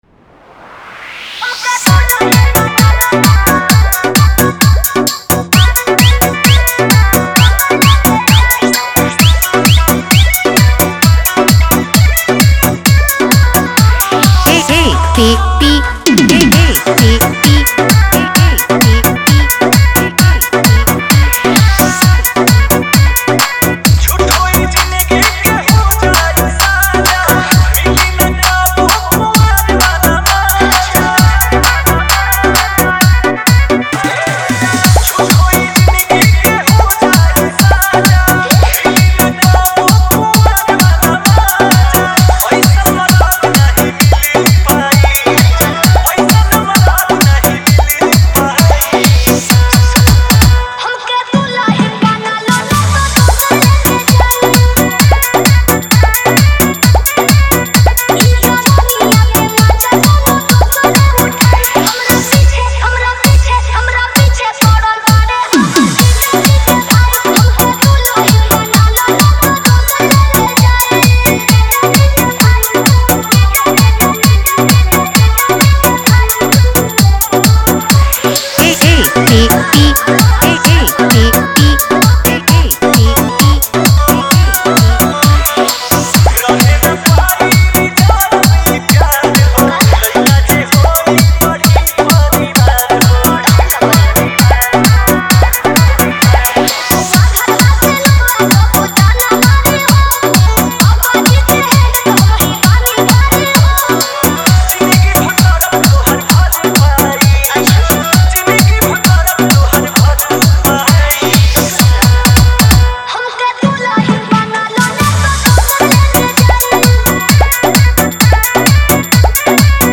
Category: Holi Dj Songs 2022